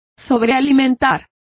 Complete with pronunciation of the translation of overfed.